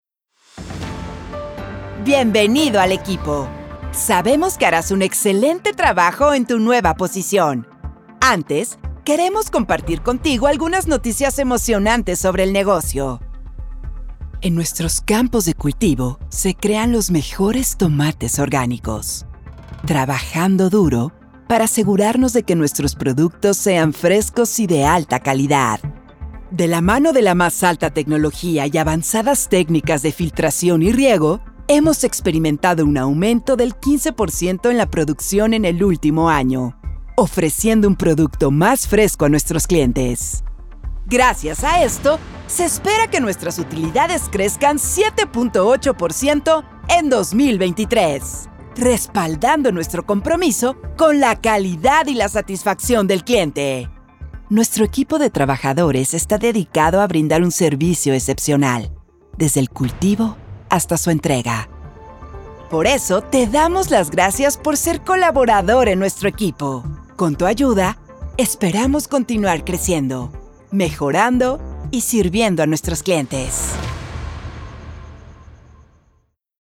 Transforma tus proyectos con una voz dinámica y versátil que conecta emocionalmente, asegurando una experiencia memorable para tu audiencia.
Demo Corporativo
Micrófono RODE NT1-A